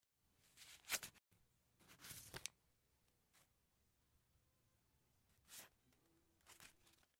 处理金钱问题 " 把钱放进钱包（票据）里
标签： 计费 比索 钱包 现金 美元
声道立体声